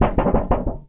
metal_tin_impacts_wobble_bend_02.wav